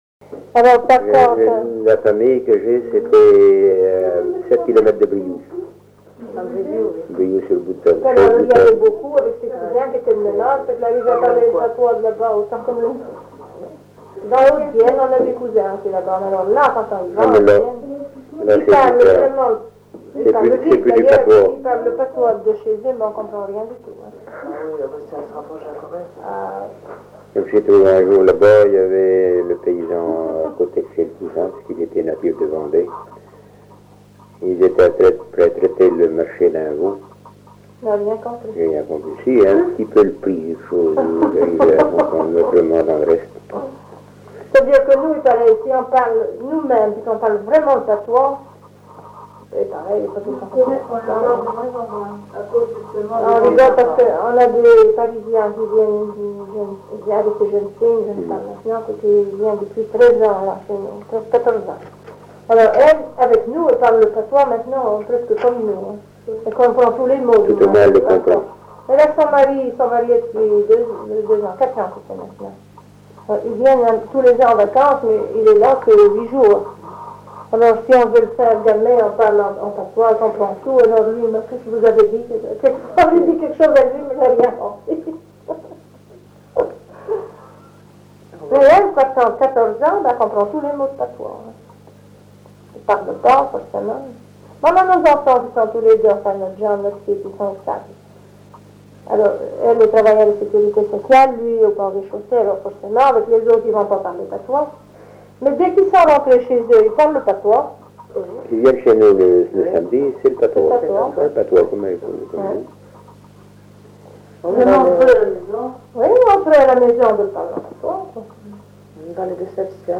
Echanges sur le patois
Catégorie Témoignage